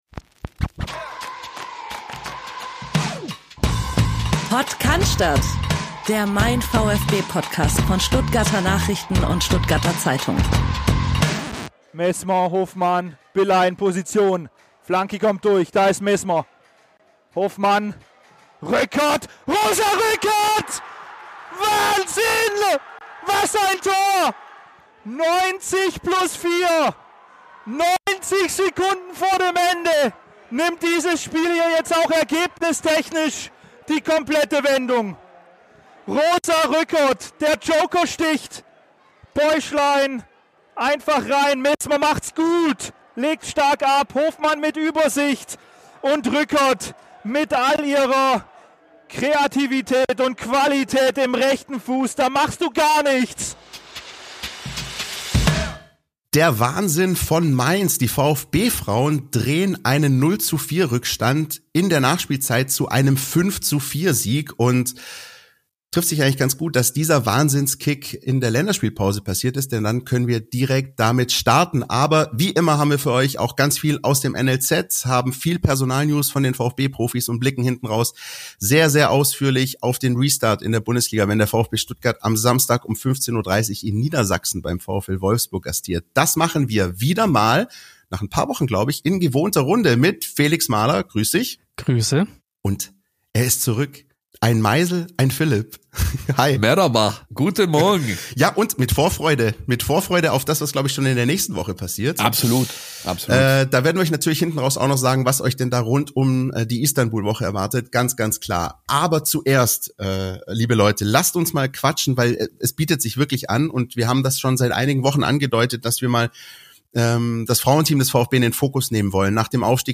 Professionell, nah dran, kompakt aufbereitet - wöchentlich sprechen unsere VfB-Reporter über den VfB Stuttgart, seine aktuelle Form und die Themen, die die Fans bewegen.